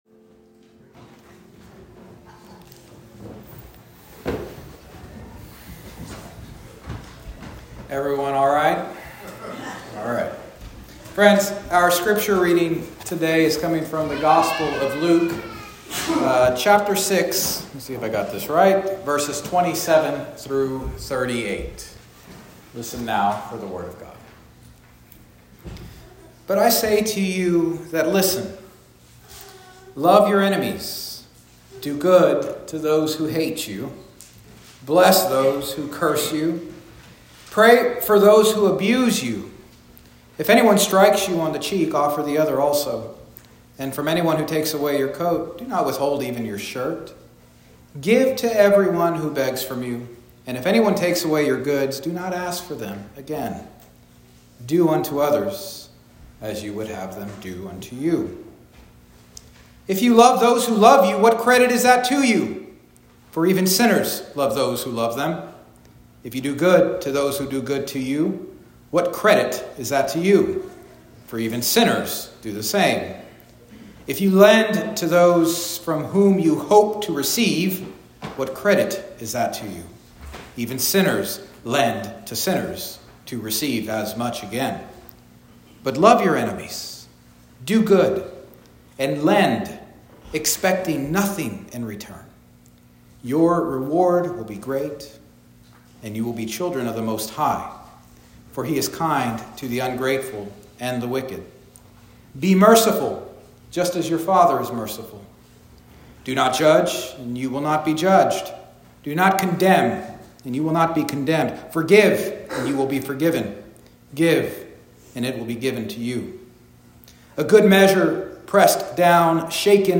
Toward-a-Positive-Ethic-Sermon-2_23_25-.m4a